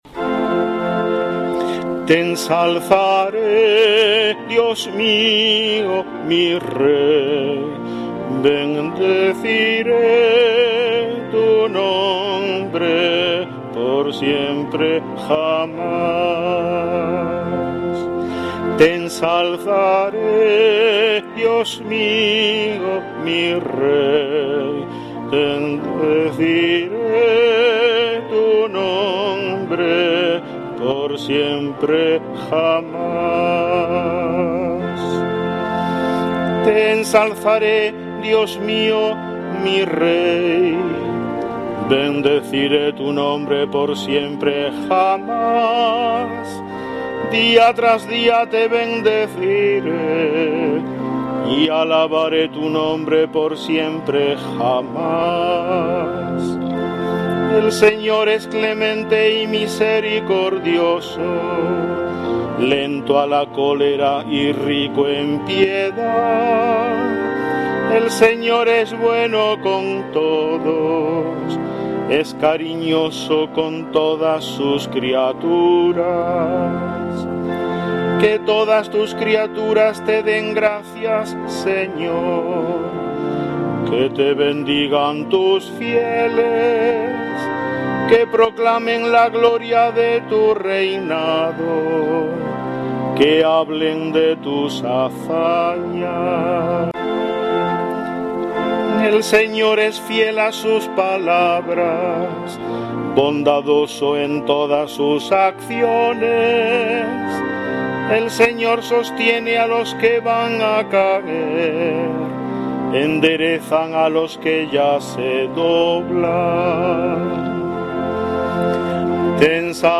Salmo Responsorial 144/ 8-11; 13-14